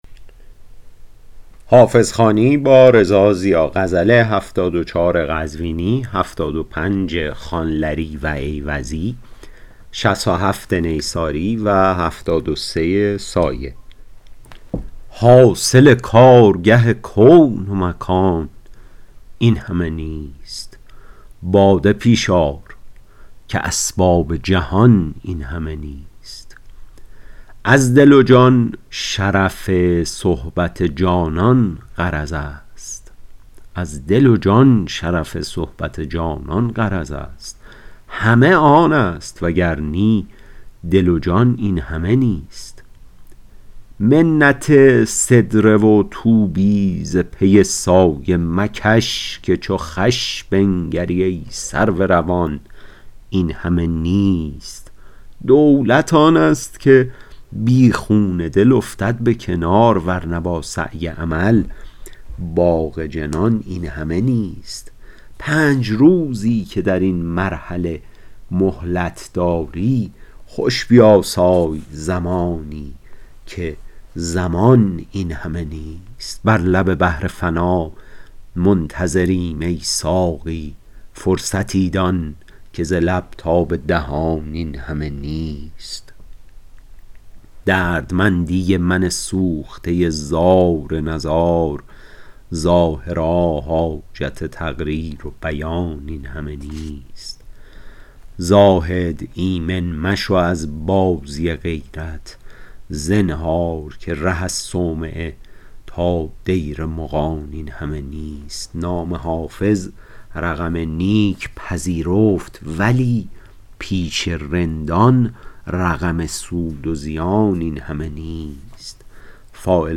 حافظ غزلیات شرح صوتی غزل شمارهٔ ۷۴